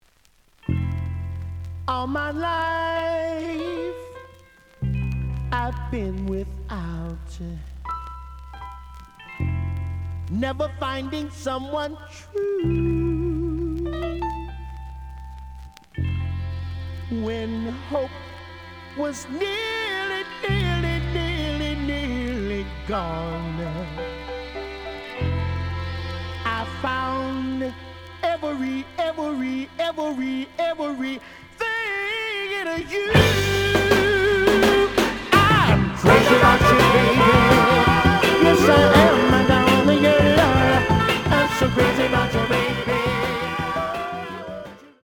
The audio sample is recorded from the actual item.
●Genre: Soul, 60's Soul
Looks good, but slight noise on both sides.